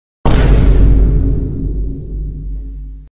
Dramatic Vine_Instagram Boom
dramatic-vine-instagram-boom.mp3